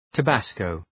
Προφορά
{tə’bæskəʋ}